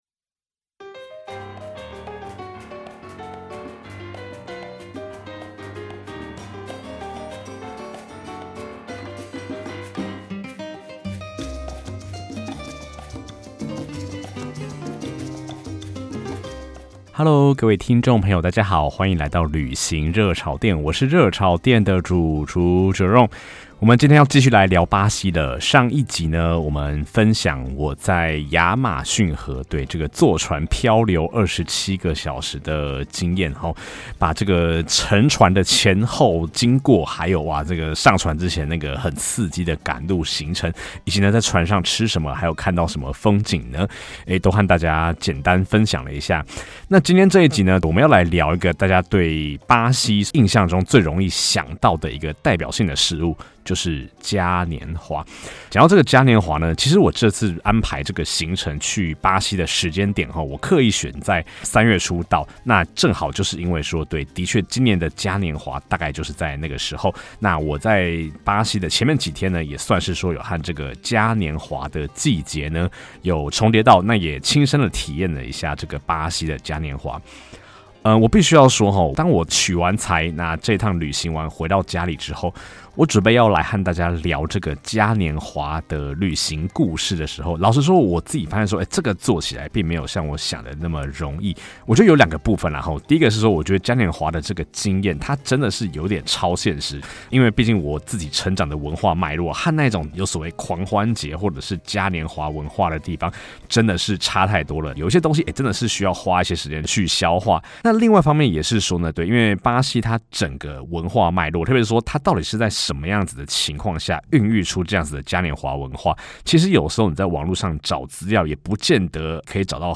基層社畜一枚，用下班後的剩餘精力旅行與做節目，大多數的單集都是自己講的。